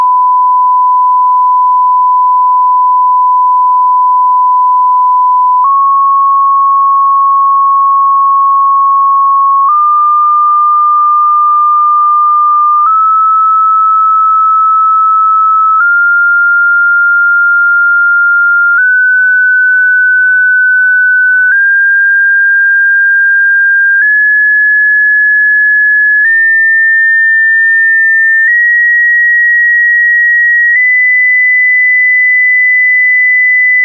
I tested on the EVM with 48KHz sampling then record with 44.1KHz using tone from audio precision that is swept from 1Khz to 2Khz in 100Hz step and I don't hear any click when the tone changes.
EVM-recorded-slave-mode-sweep-frequency-1K_2D00_2K.wav